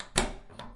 家庭 " 杠杆式门锁打开 01
描述：门杆闩锁打开。使用内置麦克风录制在Zoom H4N上。
Tag: 打开 锁存器 控制杆